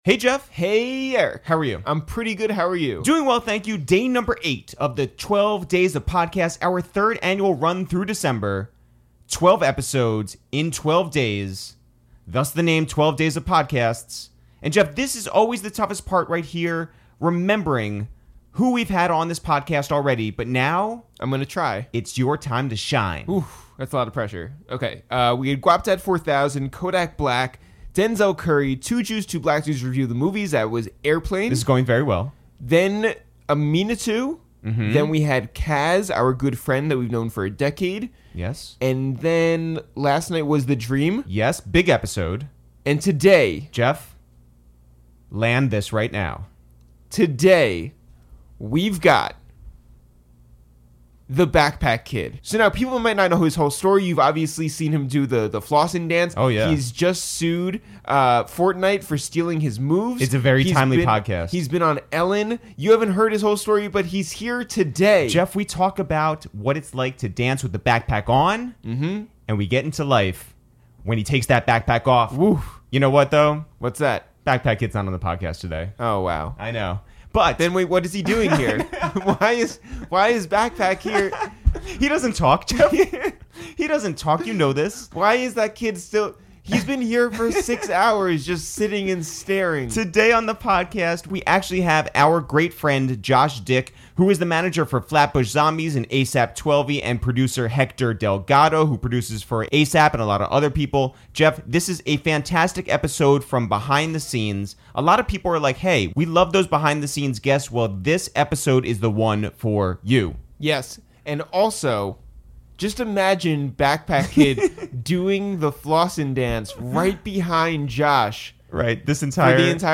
a wide-ranging and personal conversation!